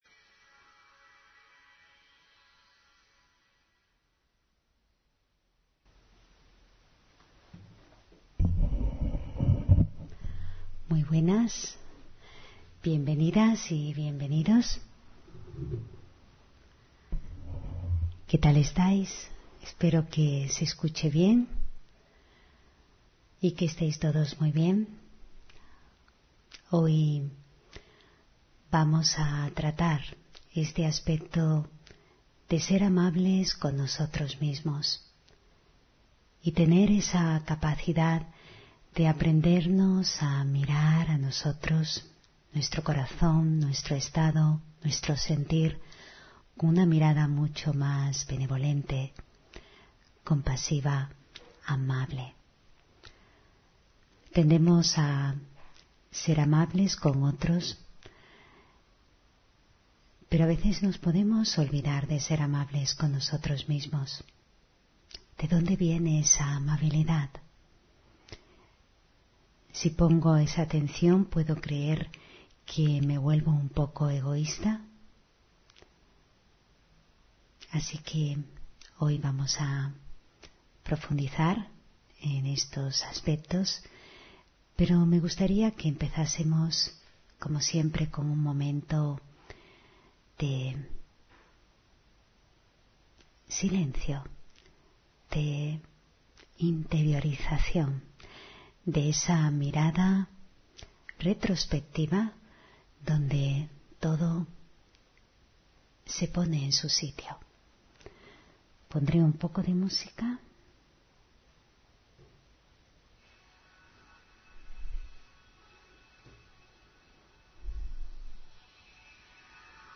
Meditación y conferencia: Ser amable contigo no cuesta (6 Noviembre 2024)